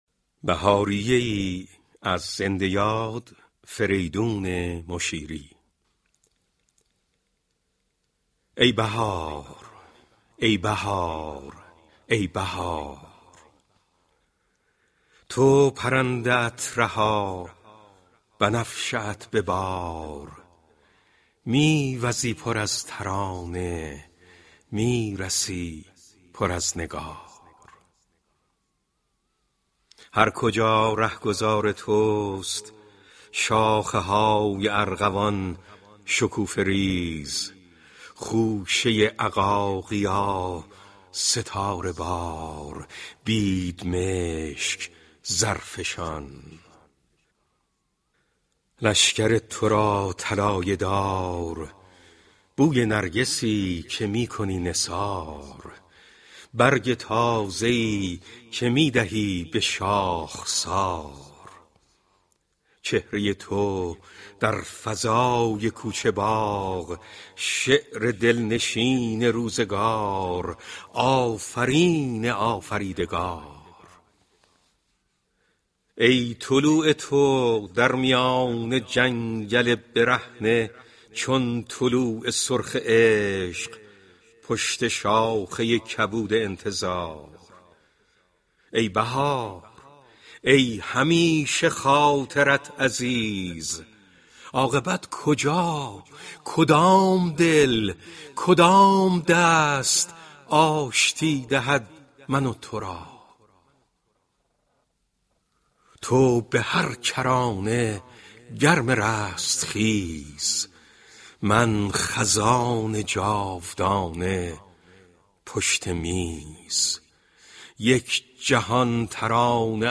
شعری از زنده یاد فریدون مشیری